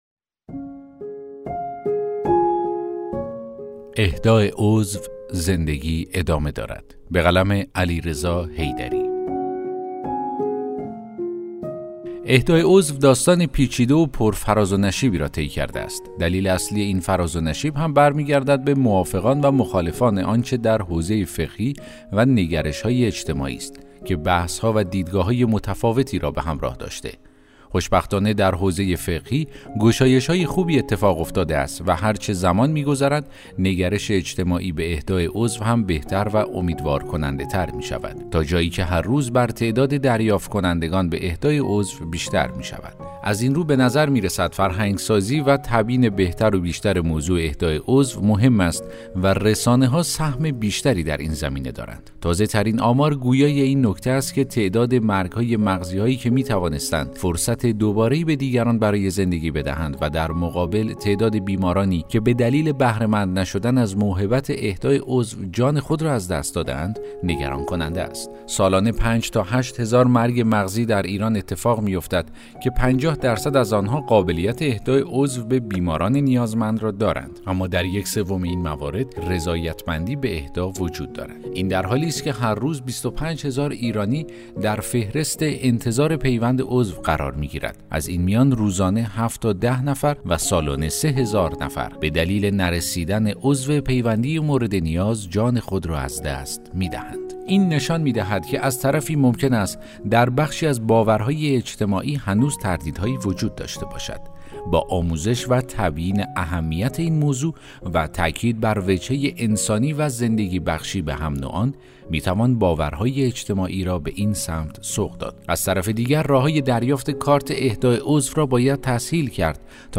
داستان صوتی: اهدای عضو، زندگی ادامه دارد